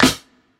Bright Snare C Key 61.wav
Royality free steel snare drum tuned to the C note. Loudest frequency: 2323Hz
bright-snare-c-key-61-zFr.mp3